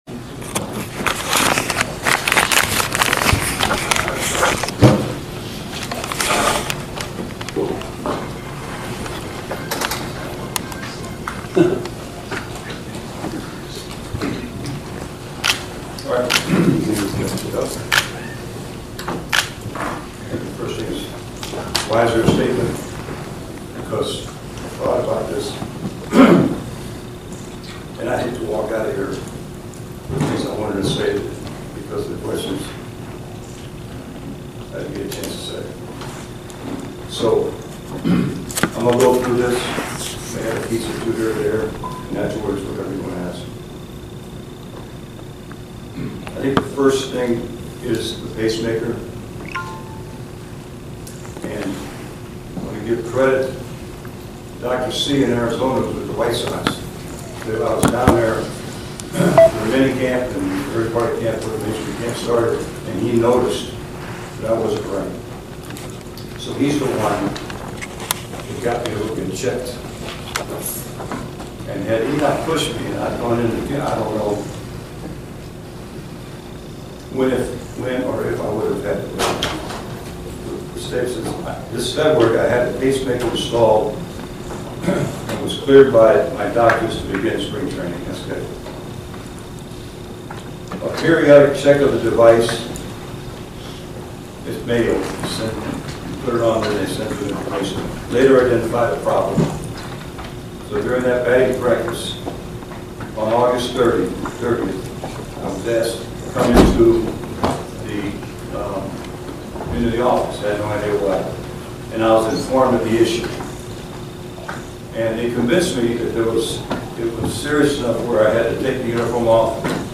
White Sox Manager Tony LaRussa resigns full uncut and unedited